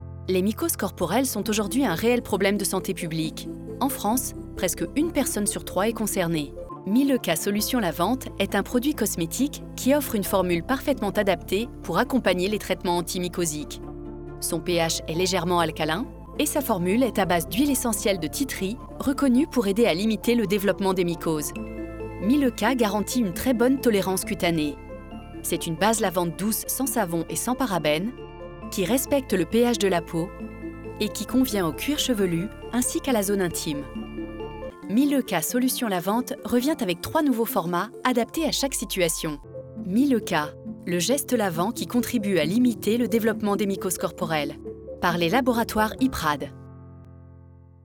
Institutionnel voix bienveillante voix sérieuse Voix sérieuse Catégories / Types de Voix Extrait : Votre navigateur ne gère pas l'élément video .